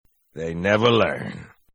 Halo Dialogue Snippets